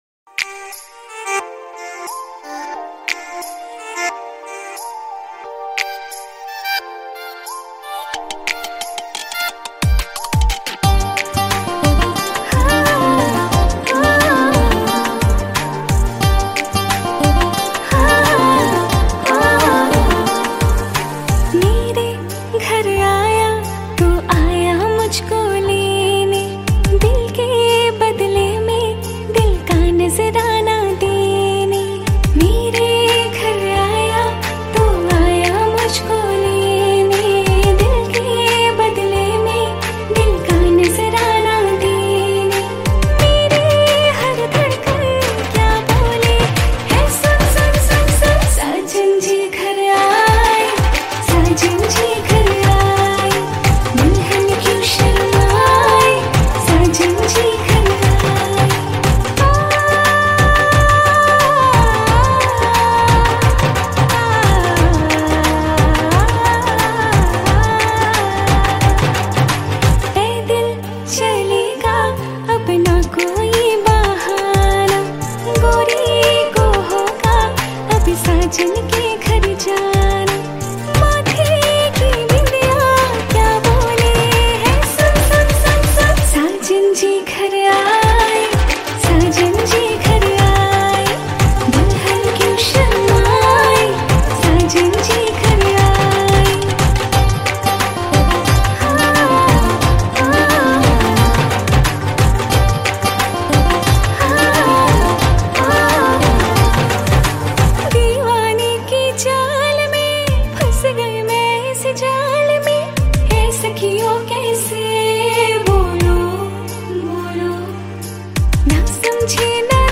New Cover Mp3 Songs 2021